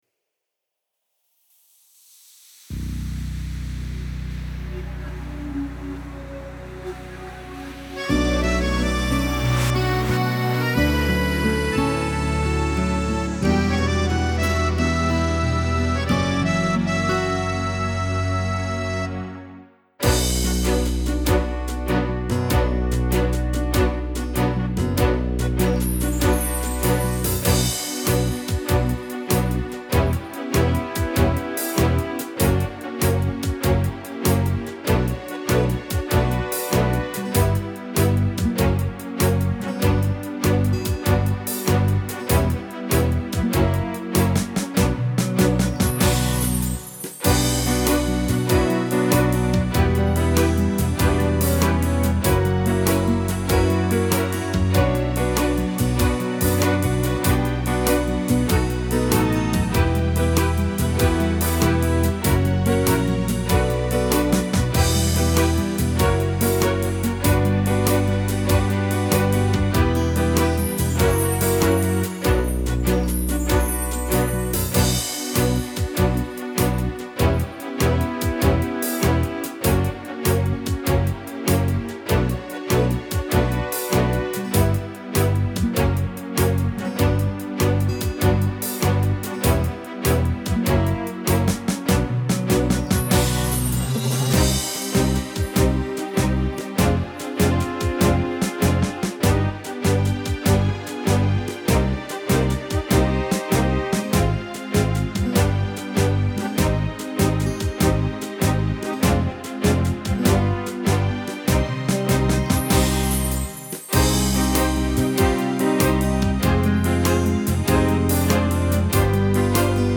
ГлавнаяПесниПесни к 9 Мая